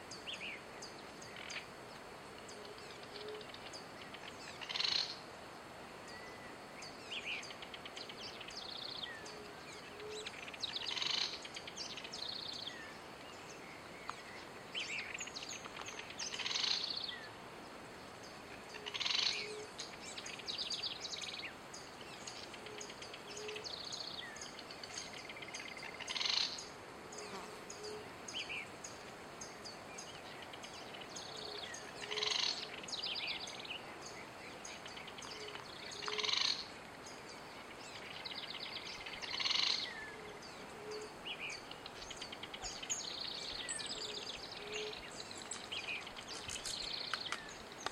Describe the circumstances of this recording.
Location or protected area: San Pedro de Colalao Condition: Wild Certainty: Recorded vocal